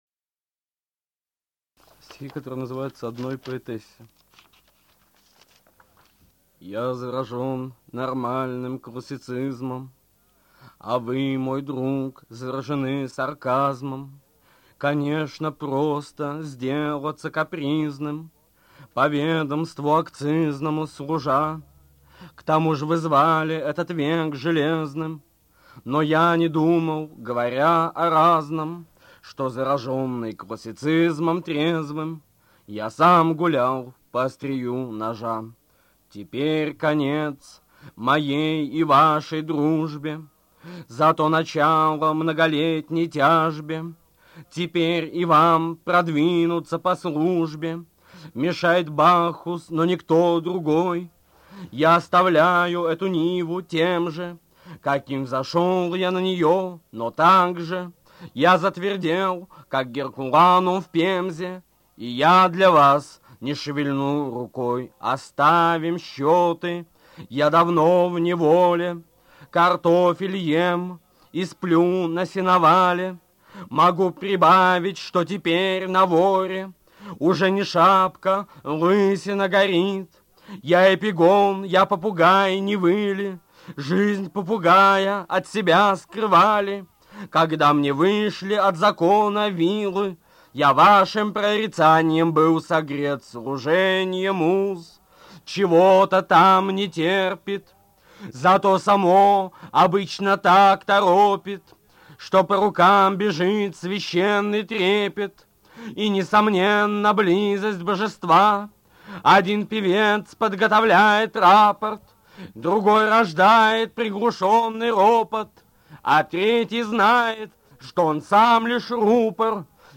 Brodskiy-Odnoy-poetesse-chitaet-avtor-stih-club-ru.mp3